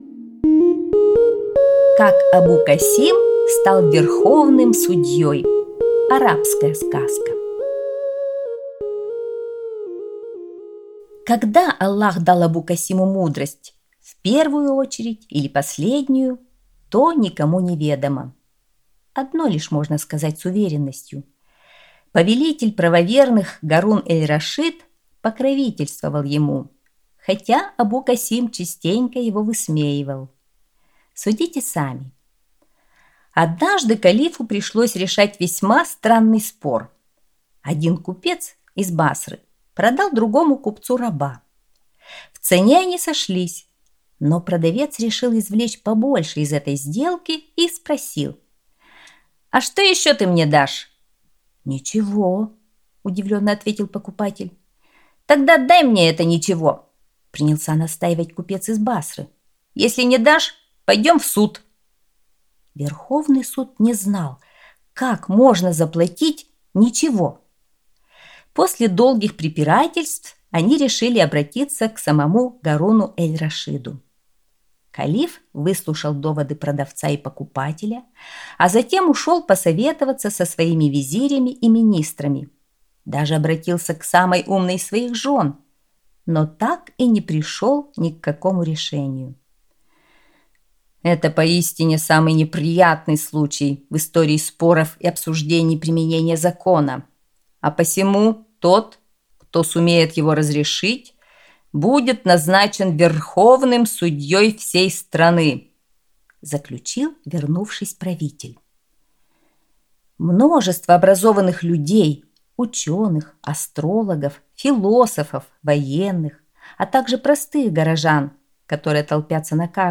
Как Абу-Касим стал верховным судьей - арабская аудиосказка